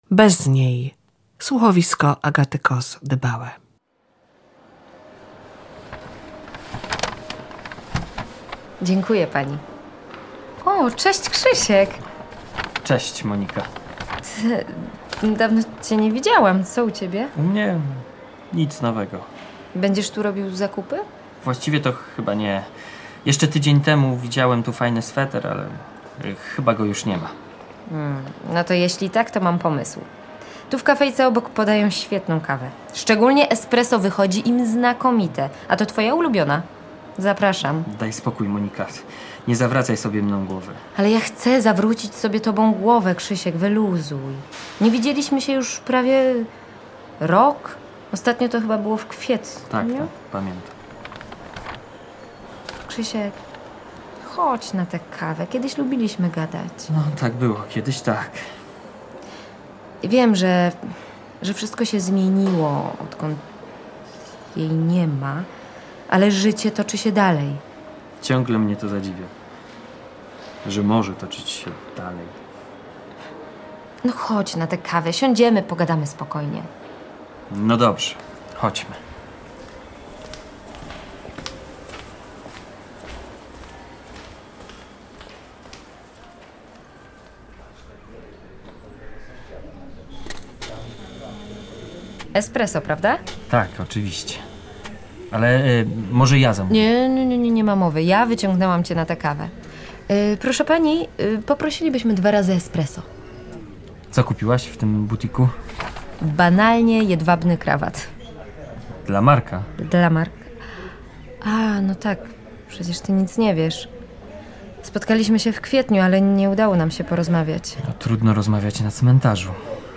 Słuchowiska Polskiego Radia Lublin „Bez niej”